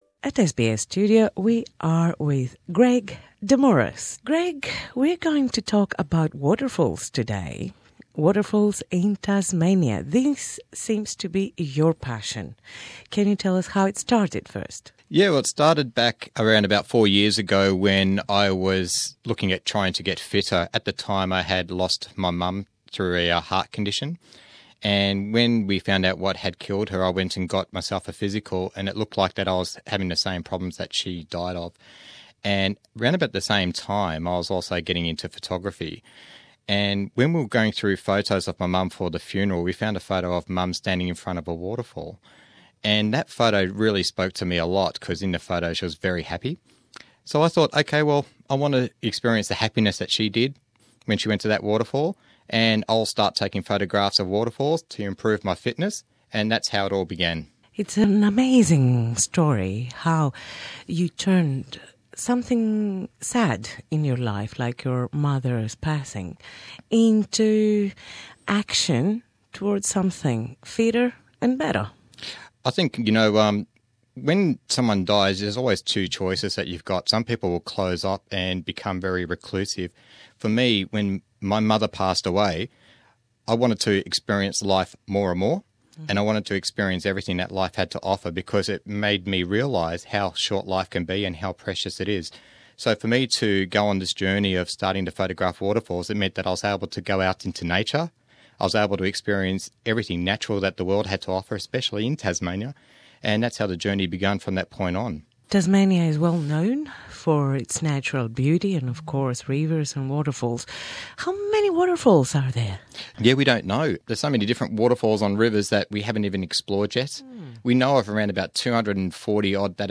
Source: SBS Radio Greek